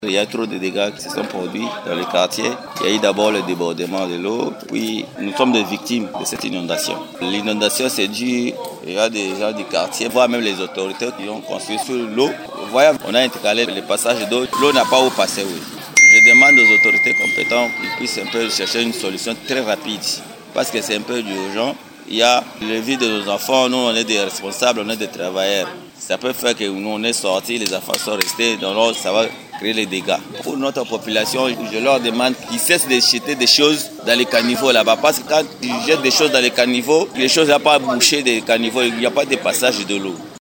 À Mbudi, dans la commune de Mont Ngafula, un habitant interrogé par Radio Okapi évoque les causes structurelles des inondations :